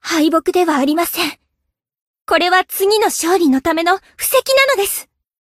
贡献 ） 分类:蔚蓝档案语音 协议:Copyright 您不可以覆盖此文件。
BA_V_Tomoe_Tactic_Defeat_2.ogg